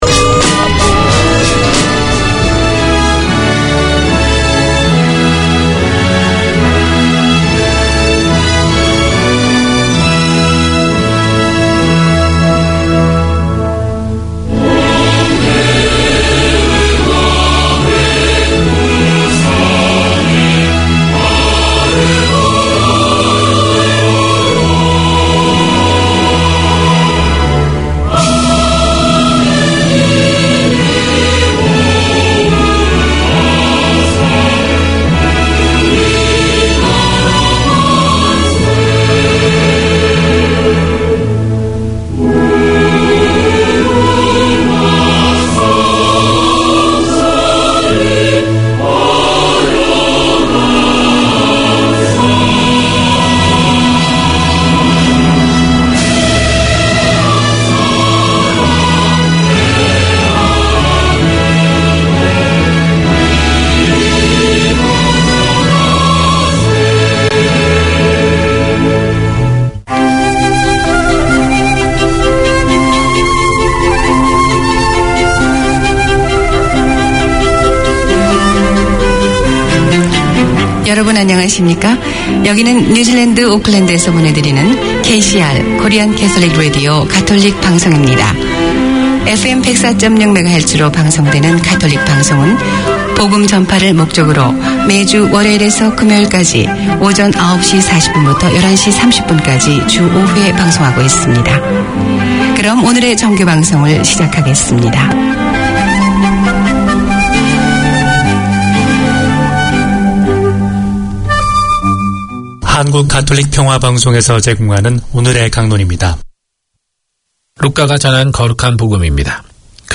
Community magazine